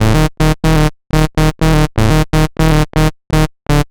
Rip bass Riff_123_A.wav